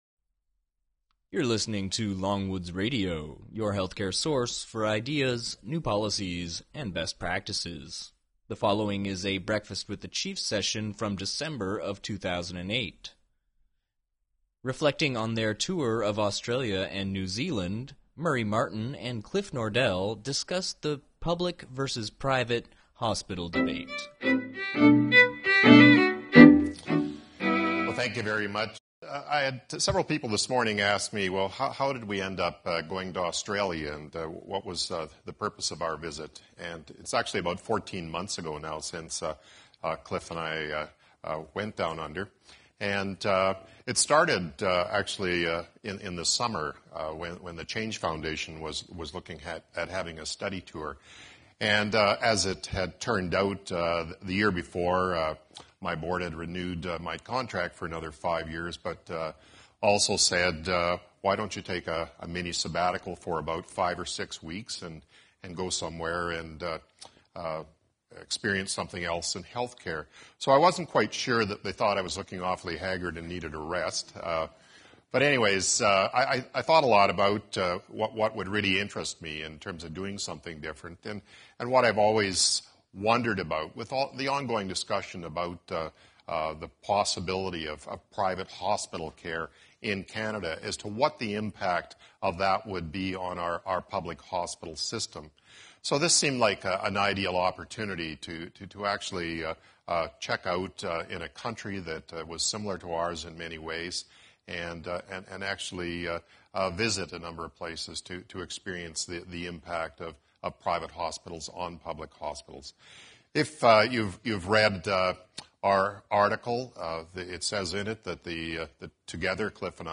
Let's Settle the Private/Public Hospital Debate: A Conversation Reflecting on a Tour of Australia and New Zealand